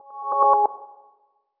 Shutdown Sound.wav